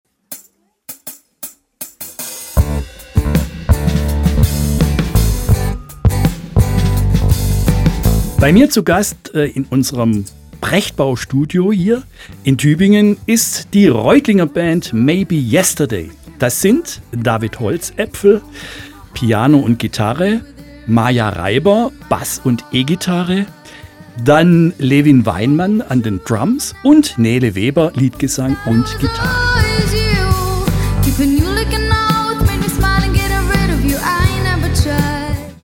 Piano und Gitarre
Bass und E-Gitarre
Drums